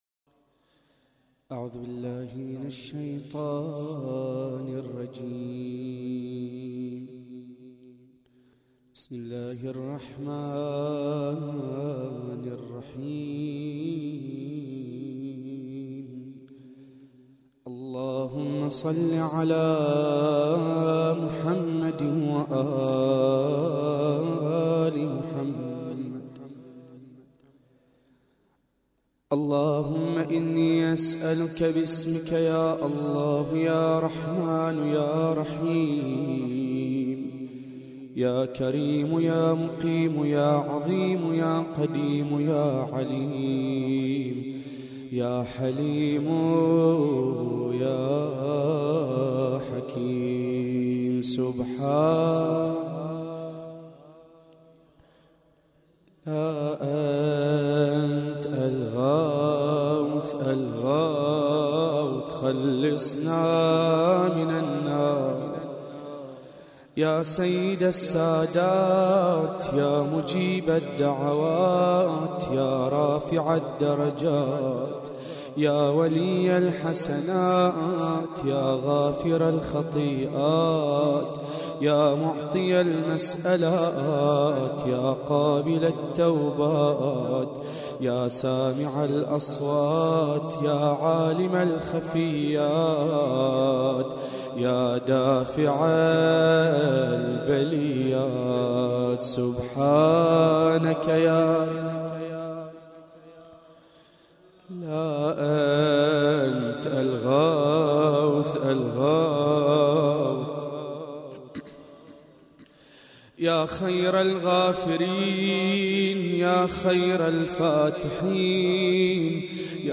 دعاء